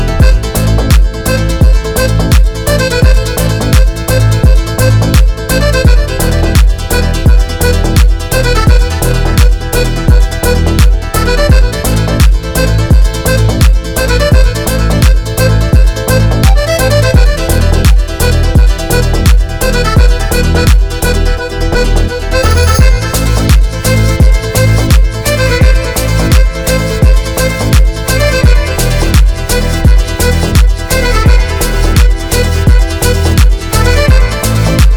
Жанр: Танцевальные / Хаус / Электроника
Electronic, Dance, House